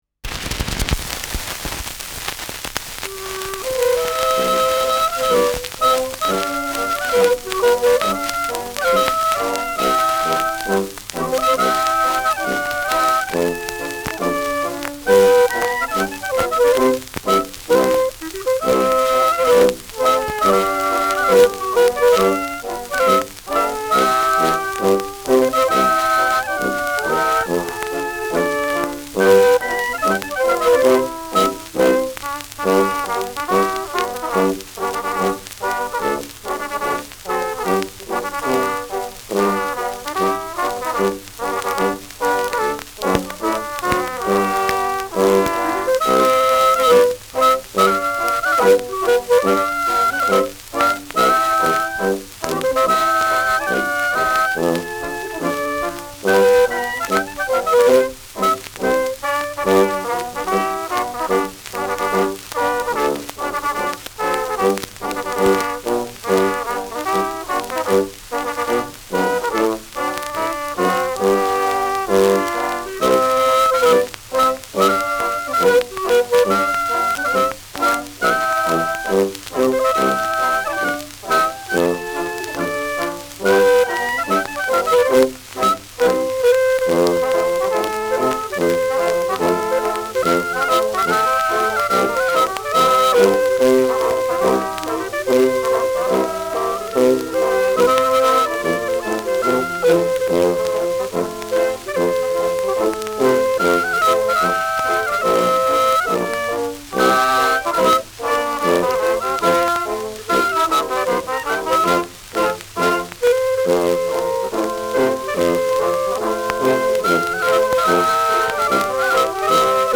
Schellackplatte
Abgespielt : Durchgehend leichtes Knacken
[unbekanntes Ensemble] (Interpretation)
Das bekannte Volkslied ist hier im Trio zu hören.